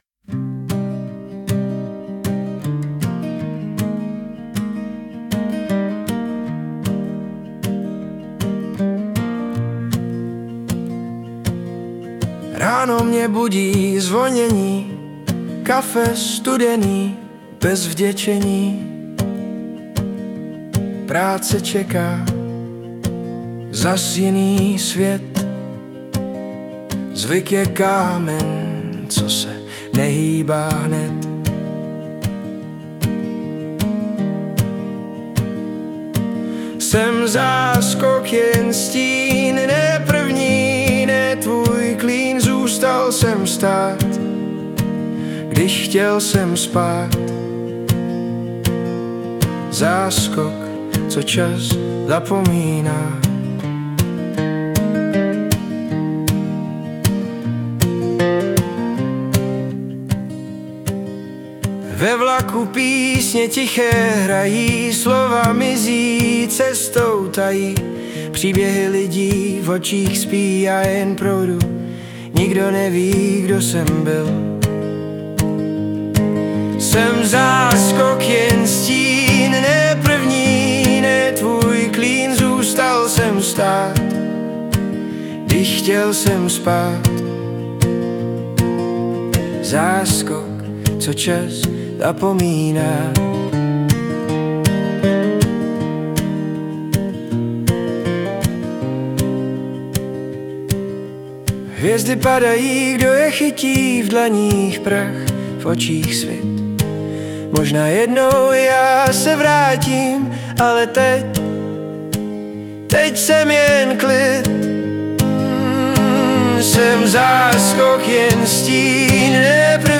Hudba a spev AI
Pohodová hudba a čeština ok.